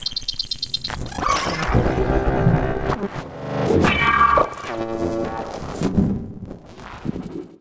Static Blerker.wav